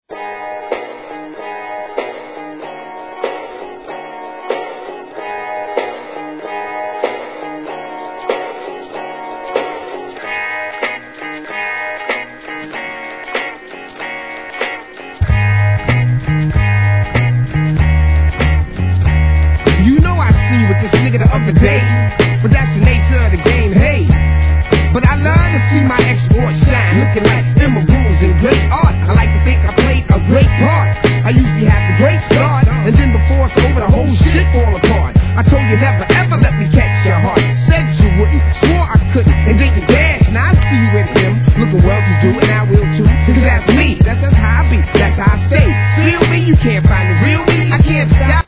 HIP HOP/R&B
拳銃の引き金を引く音を効果的にサンプリングしたハーコーSHIT!!